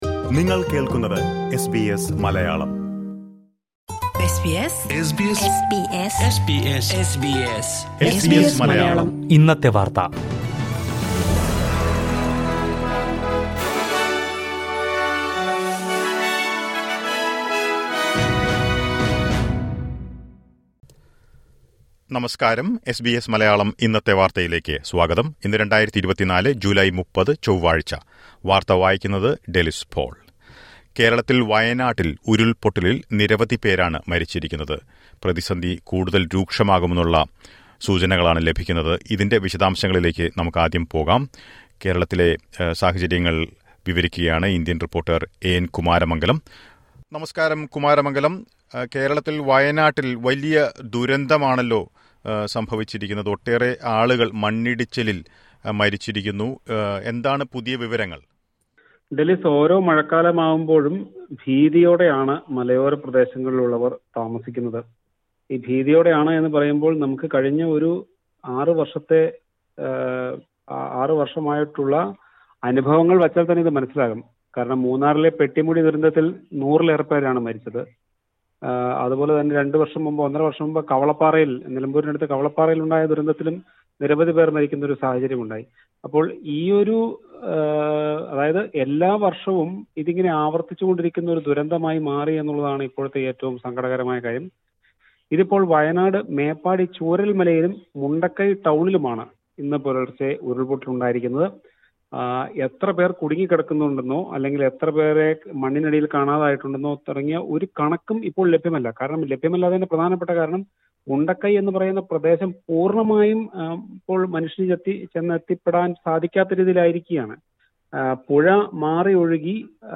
malayalam news